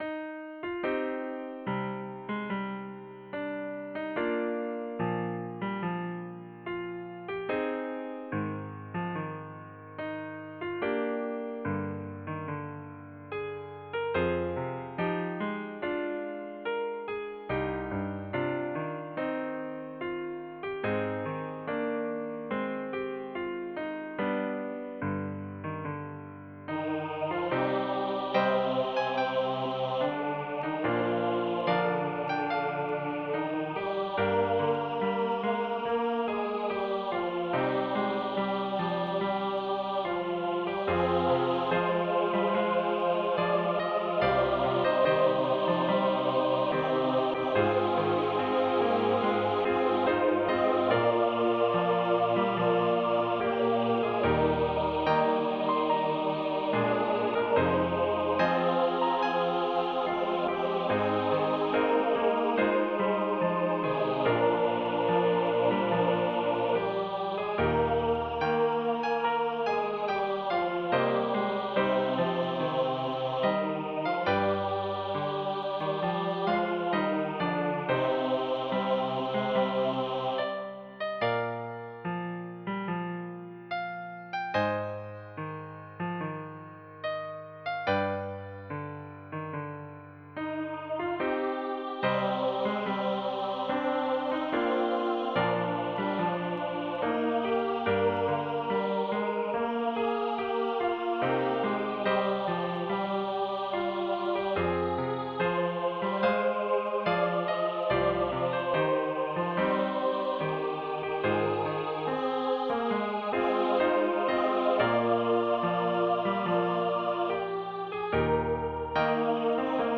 In this setting the words of the faithful followers of Christ are sung in unison and the words of Christ are sung in SATB harmony.
Voicing/Instrumentation: SATB
Easter